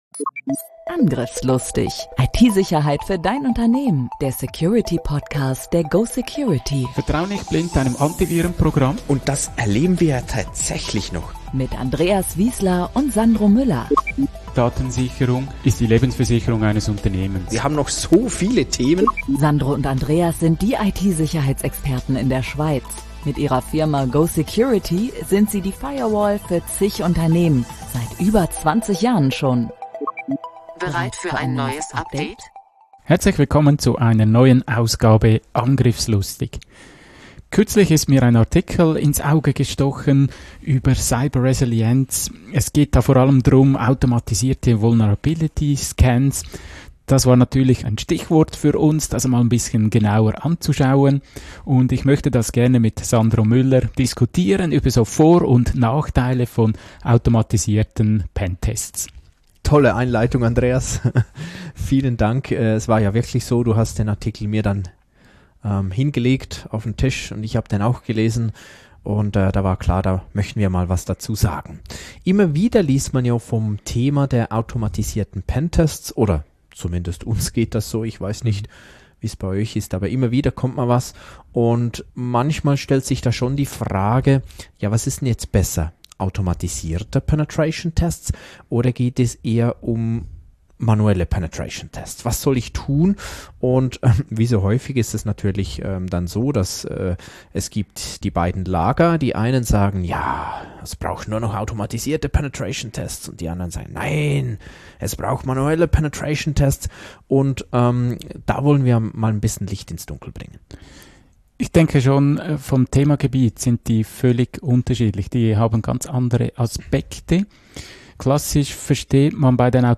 Wie gewohnt: Fachmännisch kompetent und mit einer Prise Humor.